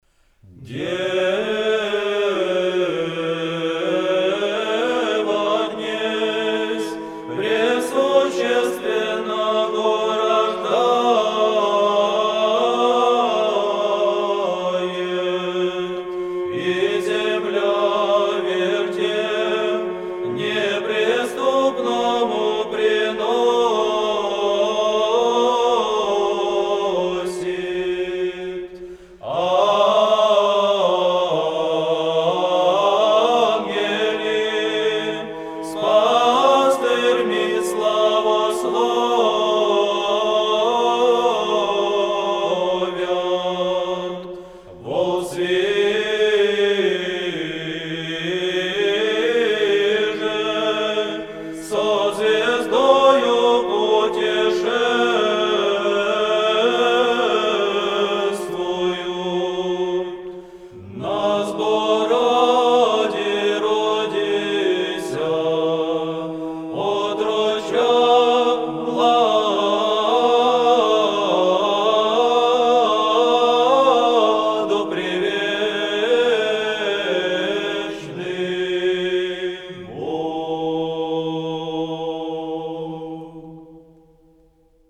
Греческий распев.